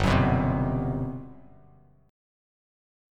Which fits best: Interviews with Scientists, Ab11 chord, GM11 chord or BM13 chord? GM11 chord